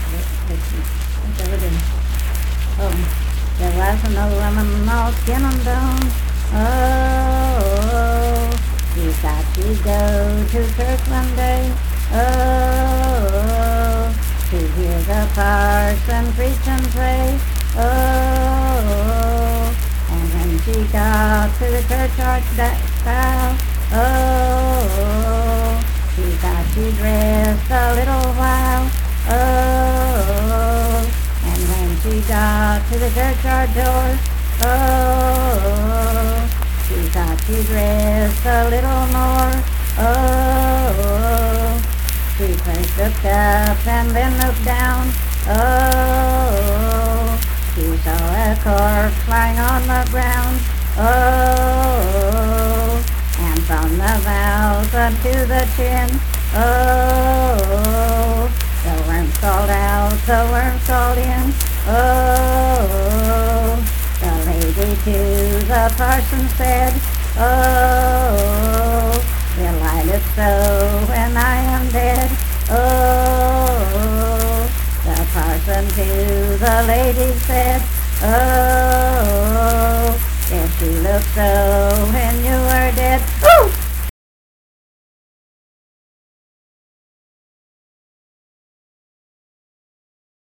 Unaccompanied vocal music performance
Death--Tragedy and Suicide, Dance, Game, and Party Songs
Voice (sung)